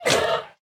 sounds / mob / panda / bite1.ogg
bite1.ogg